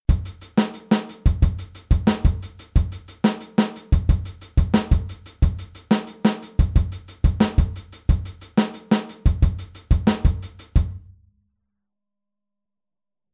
Aufteilung linke und rechte Hand auf HiHat und Snare
Groove11-16tel.mp3